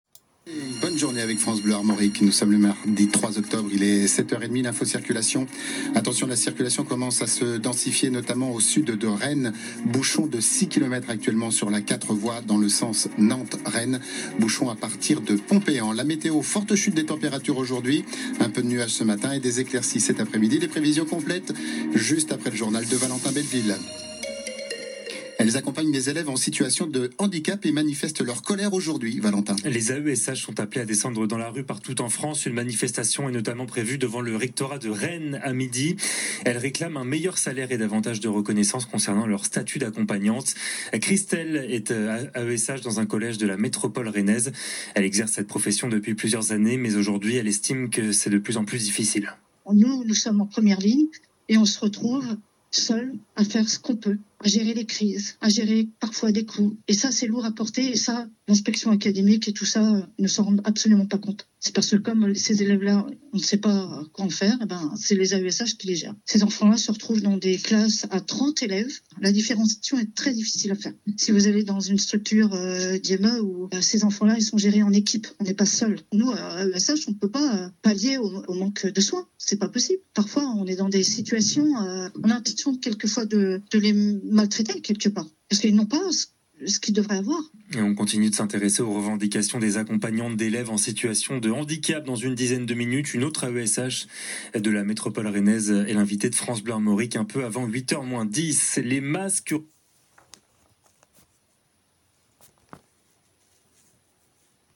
Cette mobilisation importante a été couverte par de nombreux médias (radio, TV…), qui ont notamment interviewé plusieurs AESH adhérentes FO.